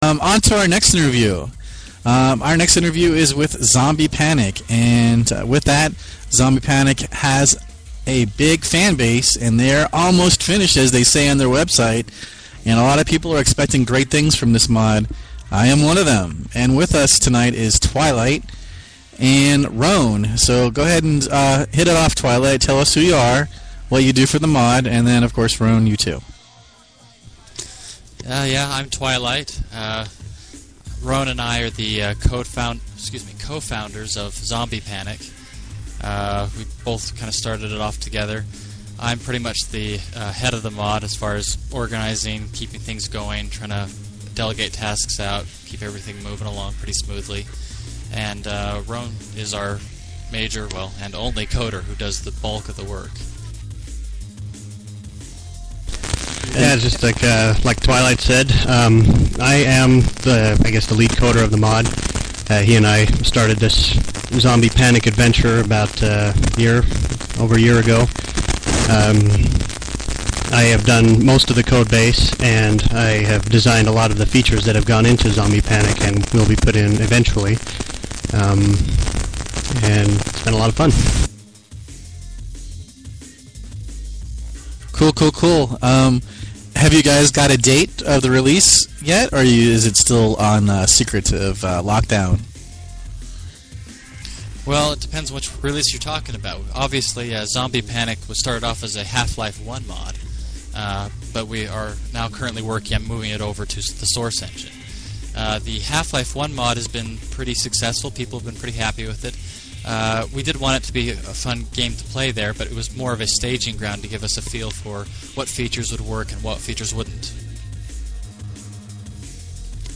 ZombiePanic_Interview.mp3